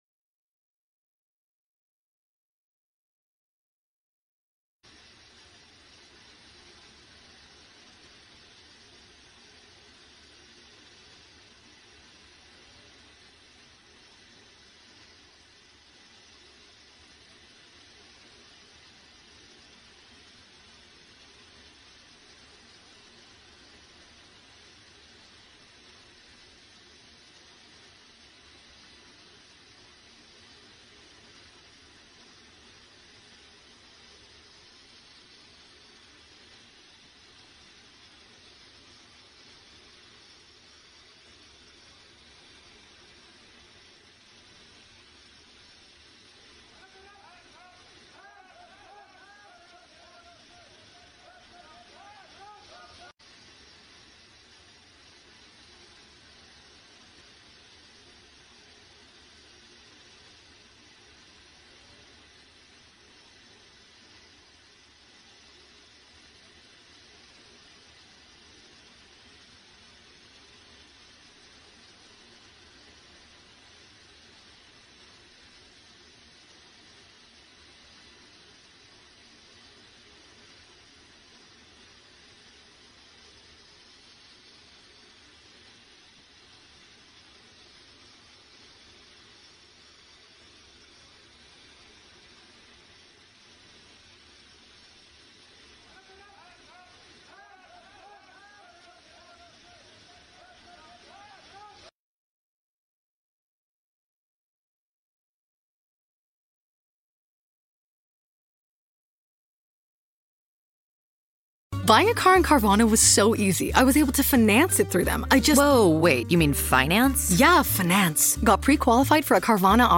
न्यूज़ रिपोर्ट - News Report Hindi / रूस के राष्ट्रपति व्लादमीर पुतिन दो दिन की भारत यात्रा, ट्रम्प हुआ नाराज़